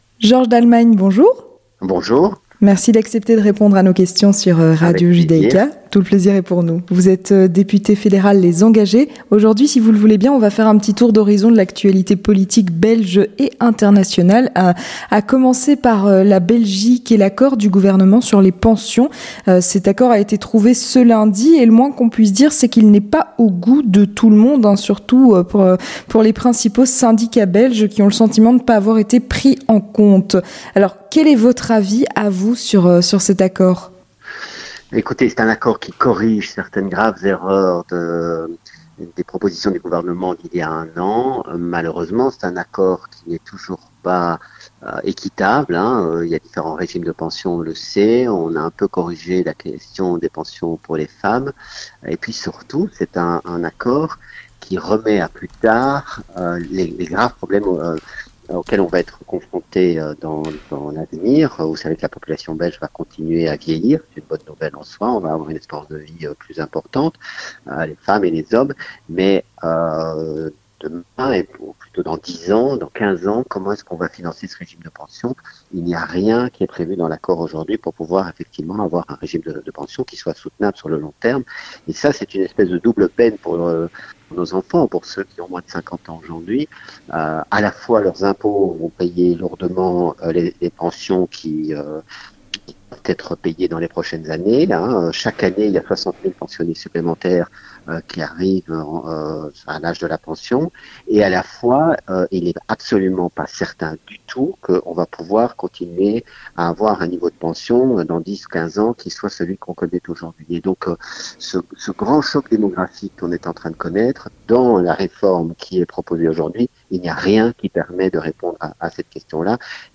Avec Georges Dallemagne, député fédéral Les Engagés